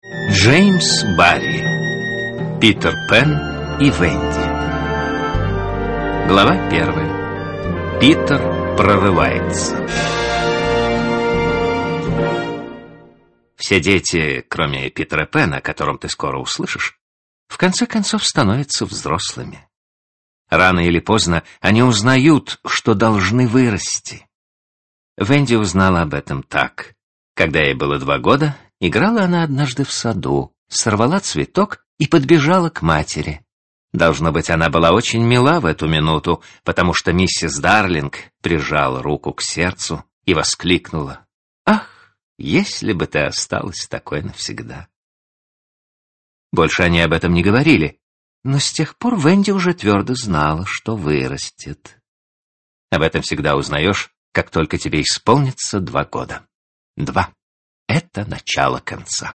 Аудиокнига Питер Пэн и Венди | Библиотека аудиокниг